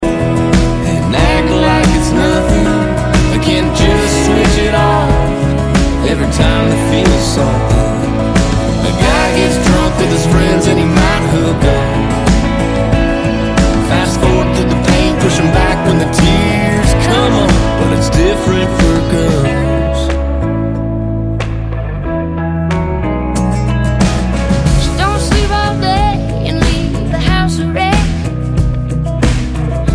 • Country
In the key of E major